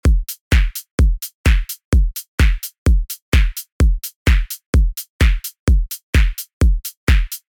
BASS HOUSE KITS
ACE_ 3-Ace_126 – Drums_Full_1